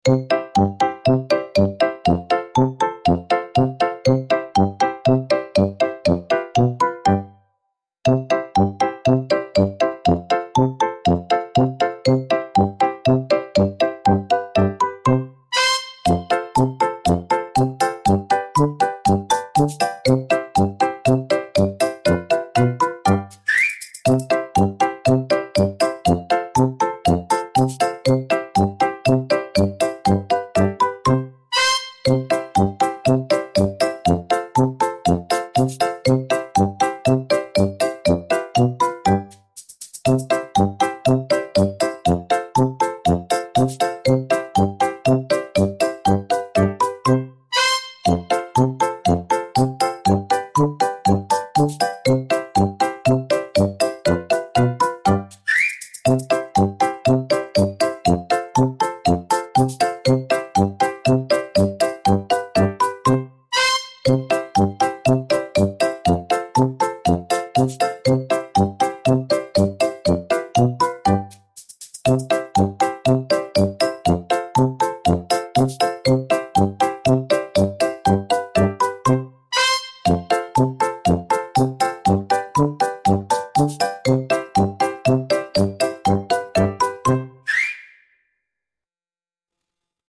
fun cartoon-style music with xylophone and whimsical brass